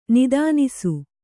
♪ nidānisu